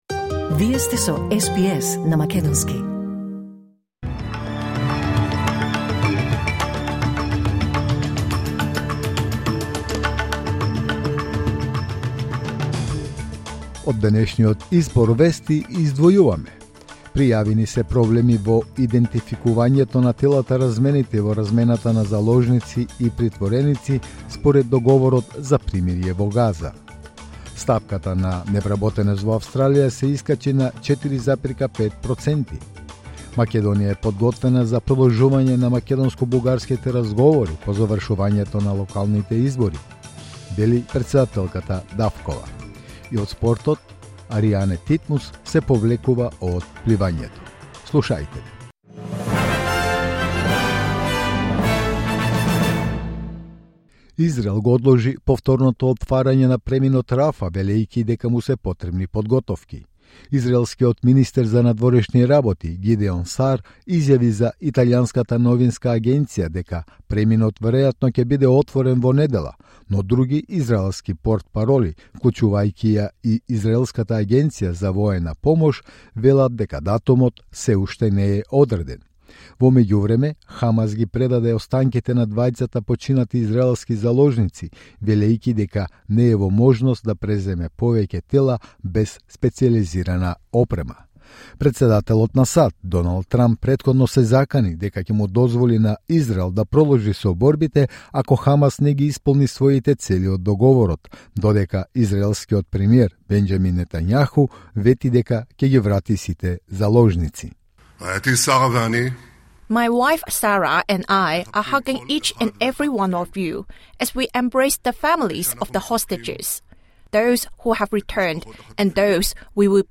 Вести на СБС на македонски 17 октомври 2025